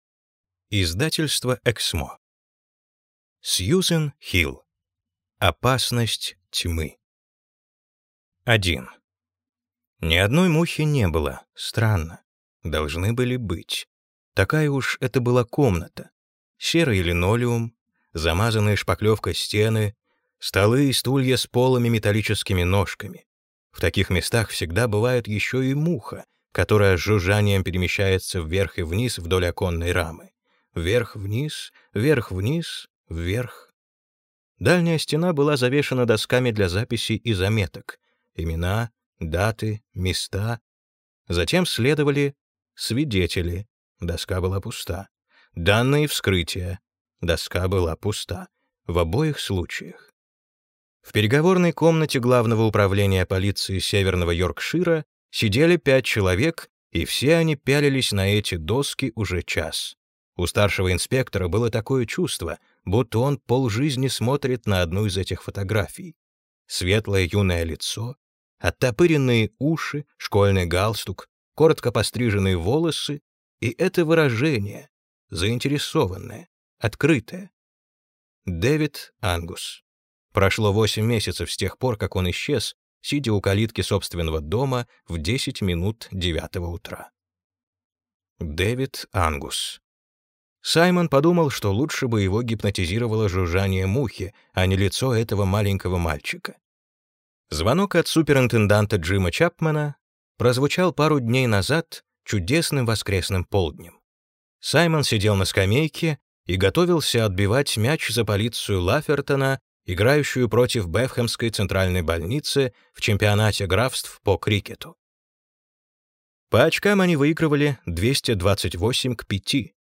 Аудиокнига Опасность тьмы | Библиотека аудиокниг